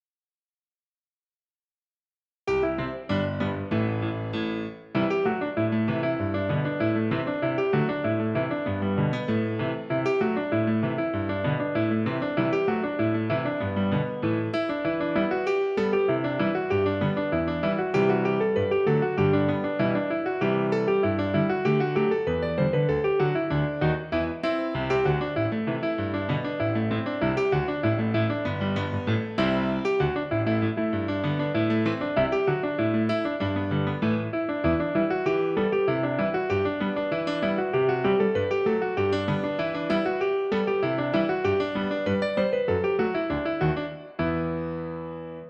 Piano accompaniment
Genre Celtic and Gaelic
Tempo 97
Rhythm Reel
Meter 4/4